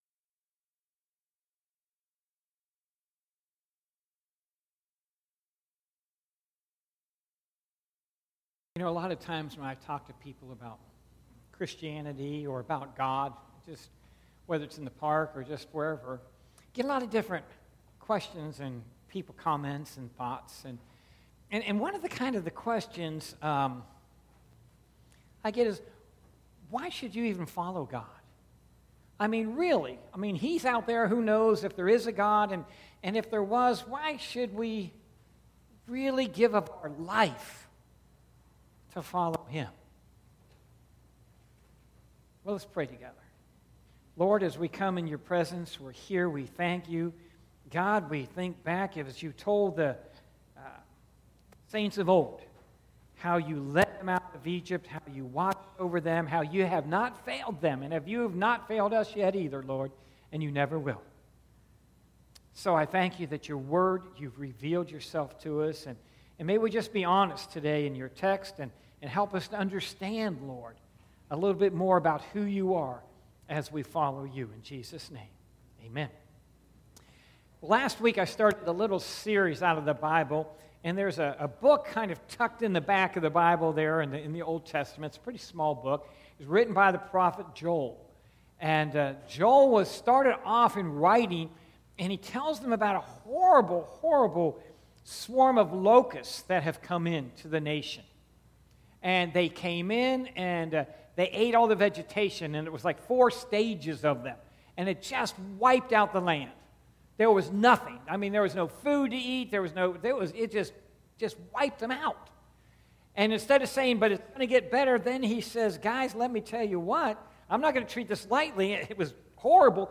Sermons - Parkland Baptist Church